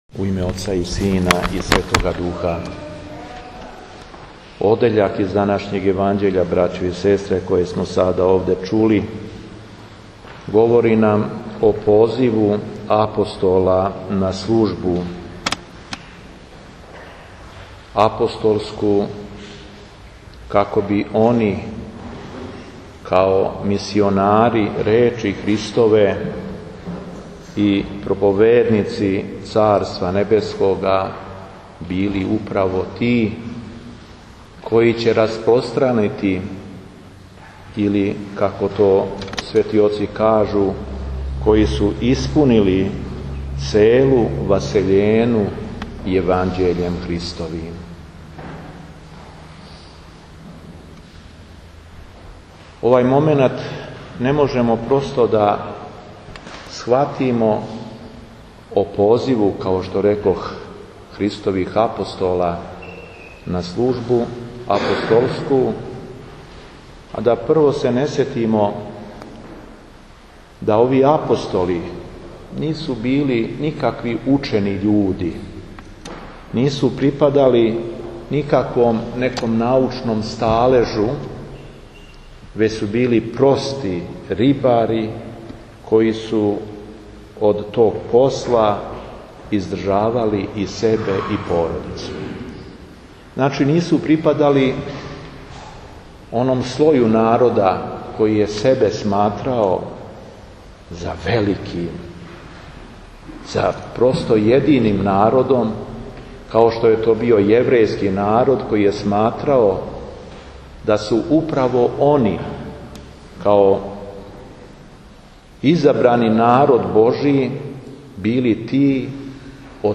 Дана 22. јуна 2014 године када наша Црква литургијски обележава прву недељу Петровог поста, Његово преосвештенство Епископ шумадијски и администратор жички Г.Јован служио је Свету Архијерејску Литургију у храму Свете Петке у Виноградима.
Беседа епископа шумадијског и администратора жичког Г. Јована